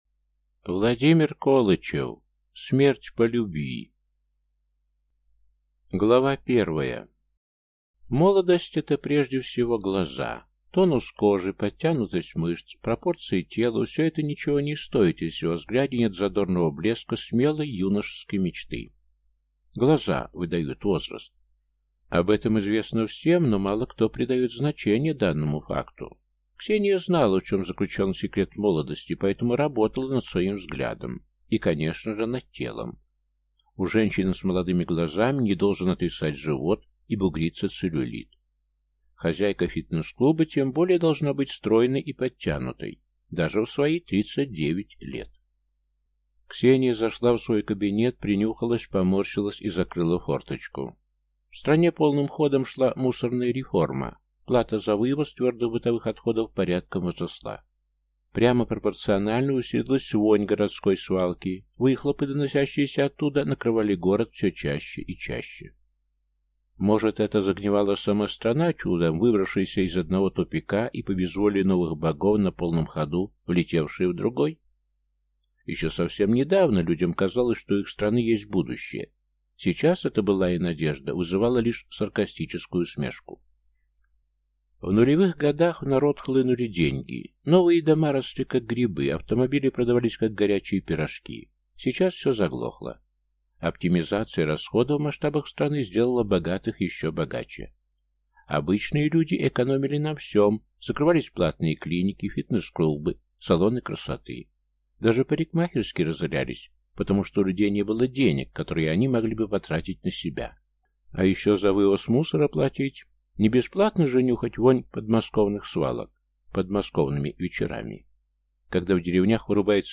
Аудиокнига Смерть по любви | Библиотека аудиокниг